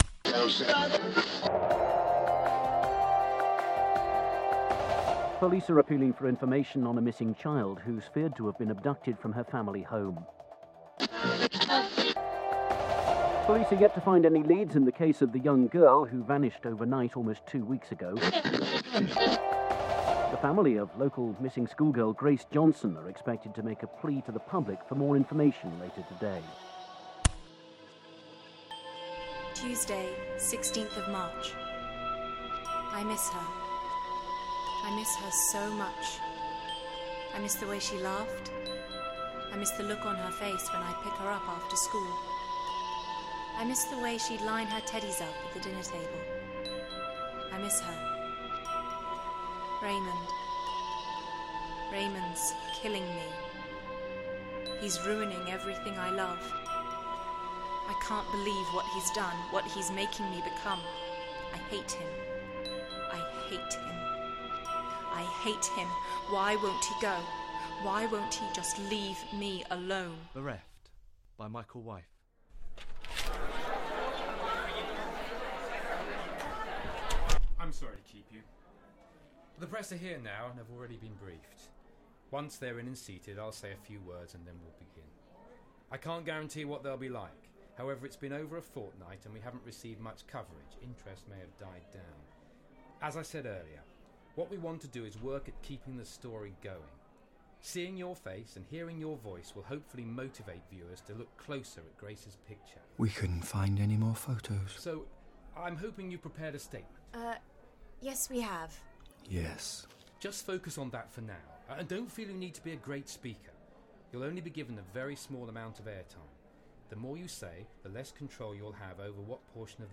Radio Drama